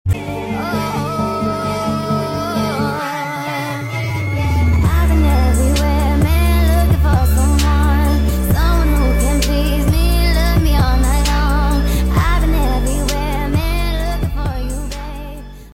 This interview<3 (audio: @:) ) sound effects free download